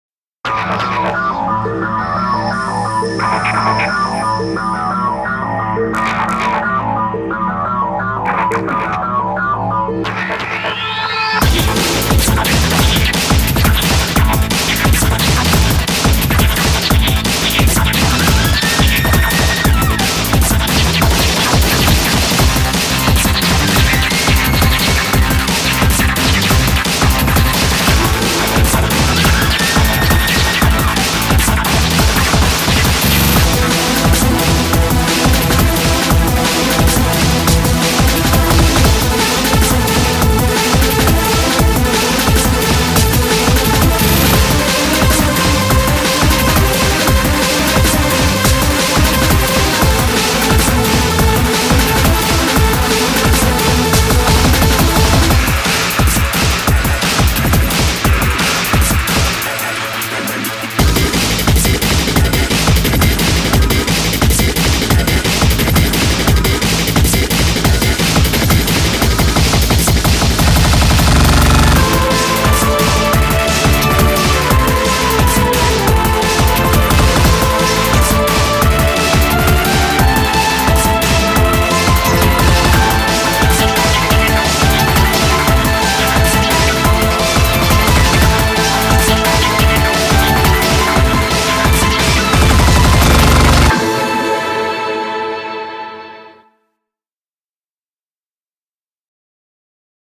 BPM44-175
Audio QualityPerfect (High Quality)
Comments[DRUM'N'BASS]